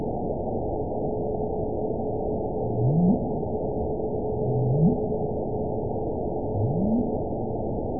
event 919569 date 01/12/24 time 22:16:00 GMT (1 year, 3 months ago) score 7.97 location TSS-AB05 detected by nrw target species NRW annotations +NRW Spectrogram: Frequency (kHz) vs. Time (s) audio not available .wav